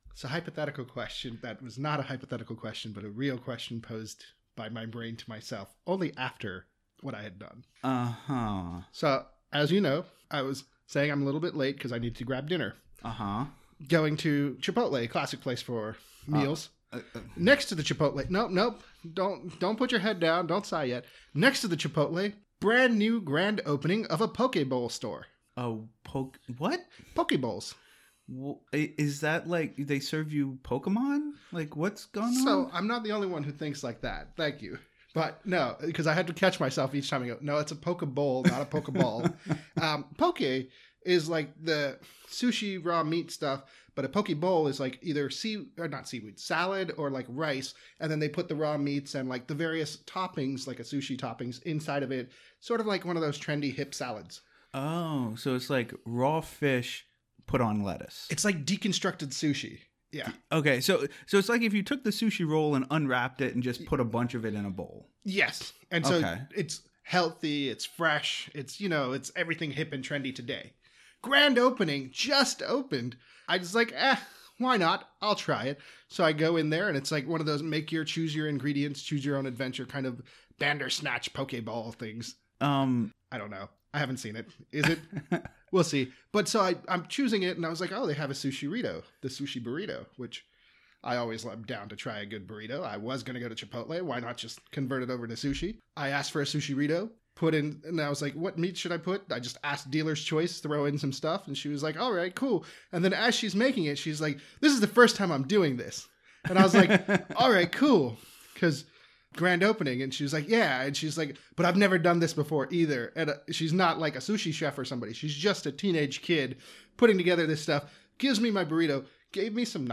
This week’s episode of Make Me A Gamer is an almost-laser-focused discussion!